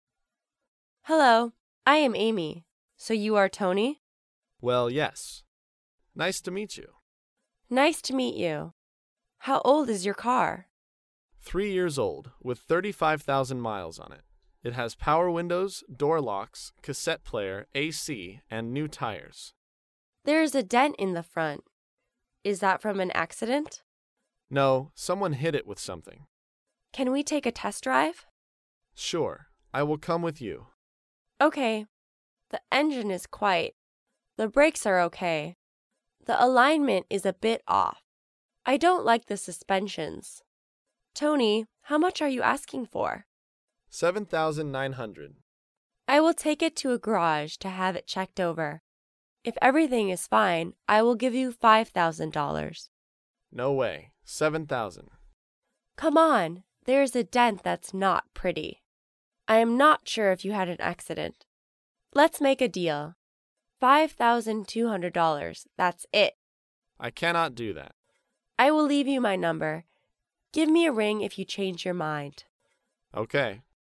dialogue
英语情景对话